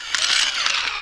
rocket_first_servo.wav